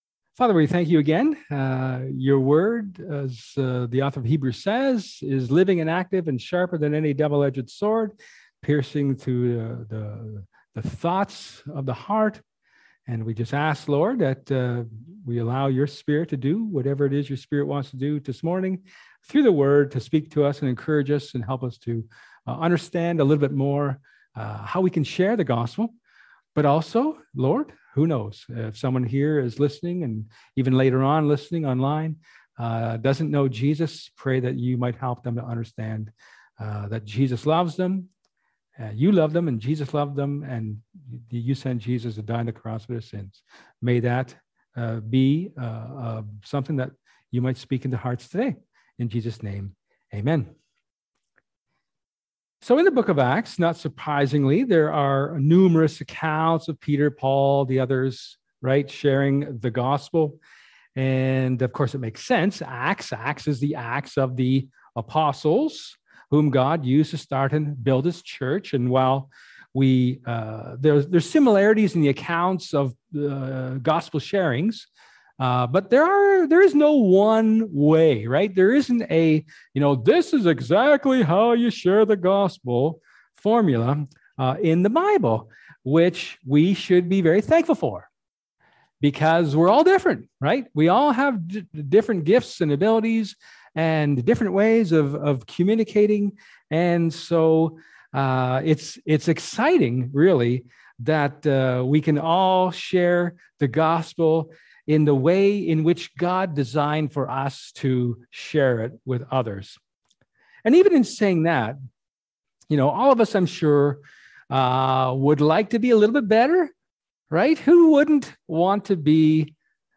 1 Corinthians 11:17-34 Service Type: Sermon